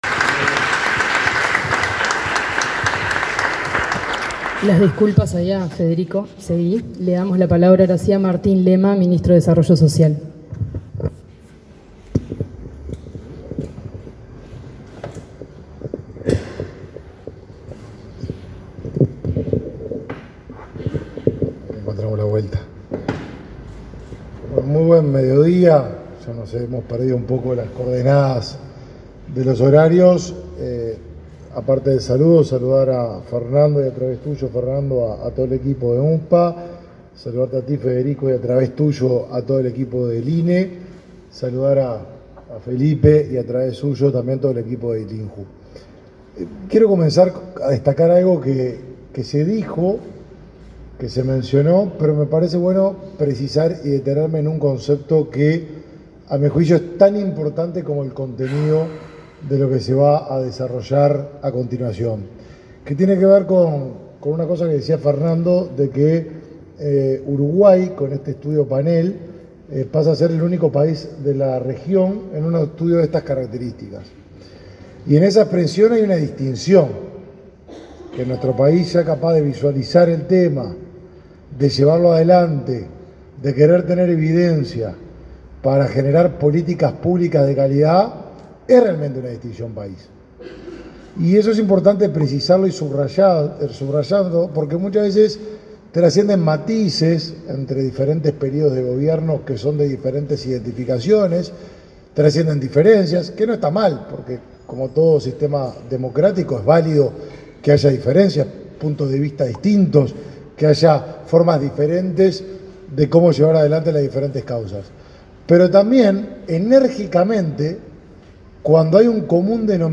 Palabras del ministro de Desarrollo Social, Martín Lema
Este jueves 12, en el Instituto Nacional de la Juventud, el ministro de Desarrollo Social, Martín Lema, participó en la presentación del primer